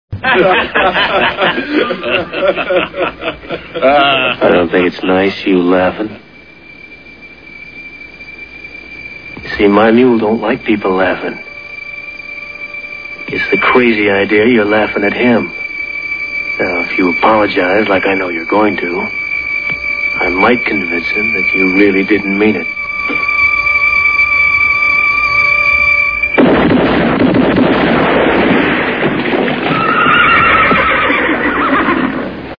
Men laughing.
Sfx: Gunshots